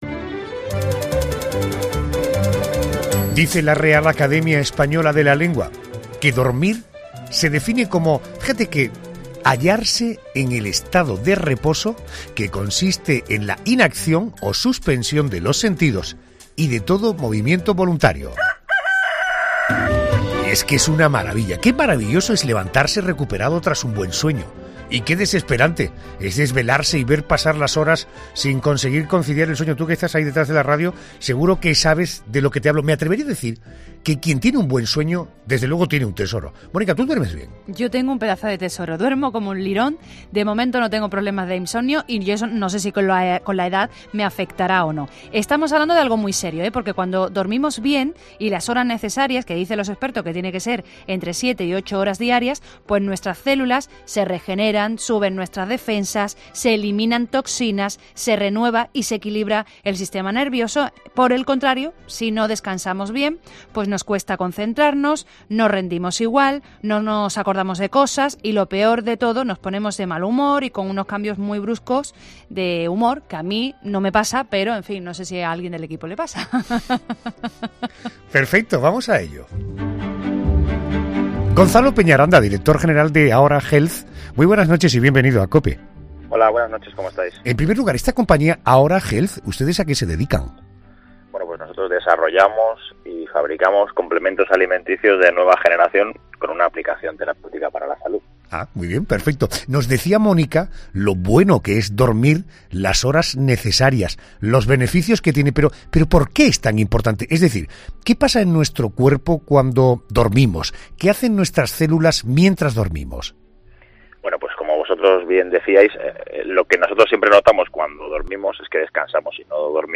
Dos expertos resuelven en ‘La Noche de COPE’ la eterna duda